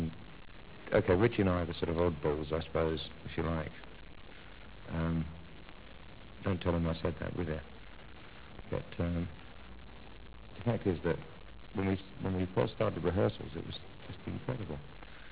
THE TOMMY VANCE INTERVIEWS